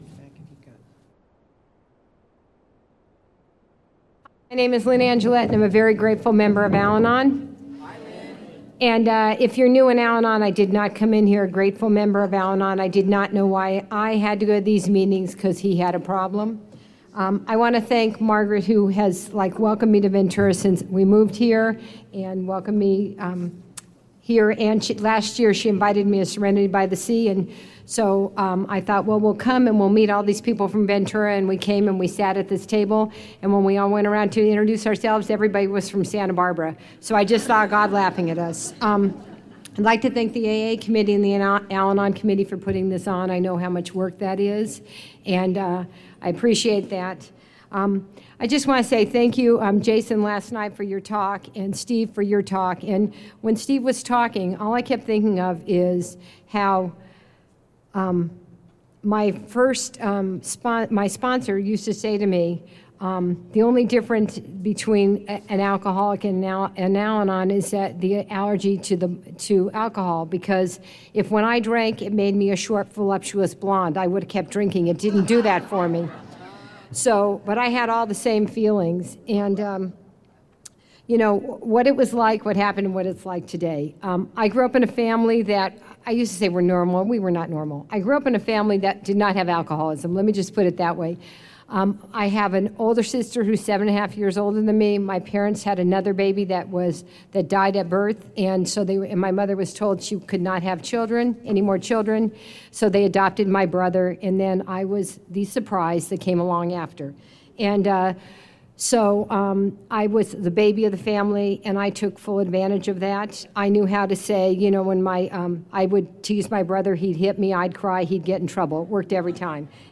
Saturday Al-Anon Luncheon Speaker &#8211